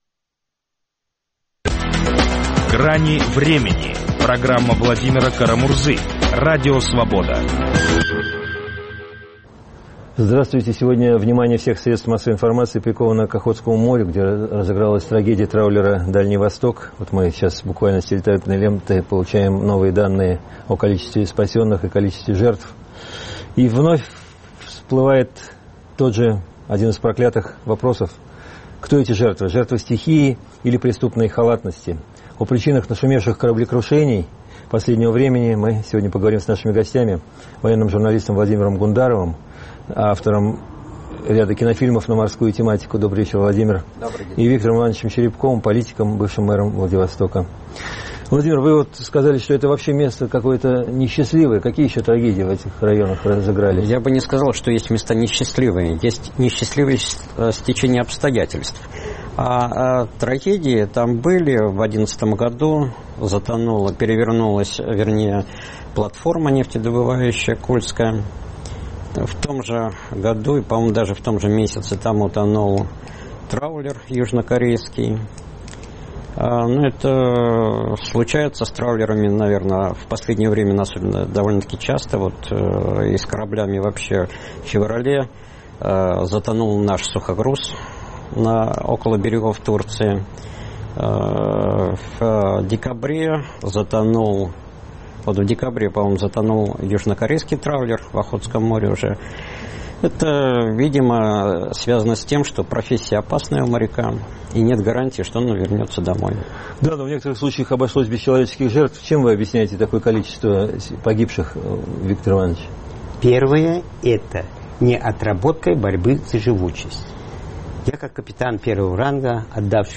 Жертвы стихии или преступной халатности? О причинах нашумевших кораблекрушений последнего времени спорят военный журналист